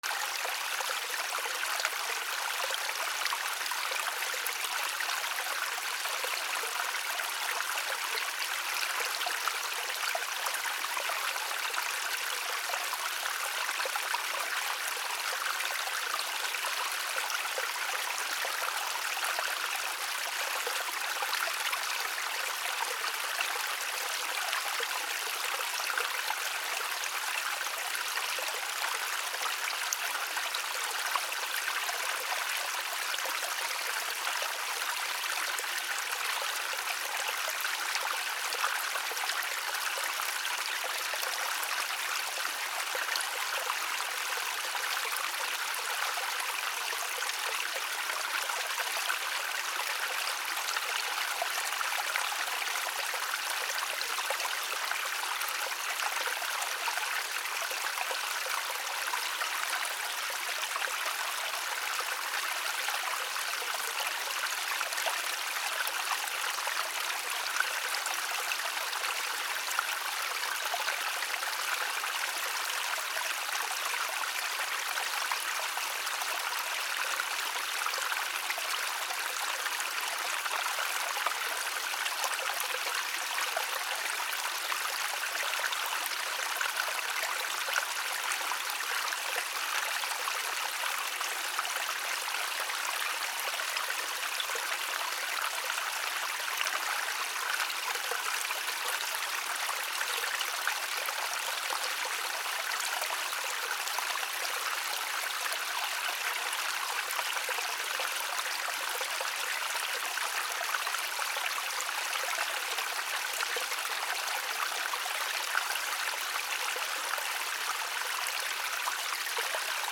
清流
/ B｜環境音(自然) / B-15 ｜水の流れ
原音あり D50 旗尾岳(天見)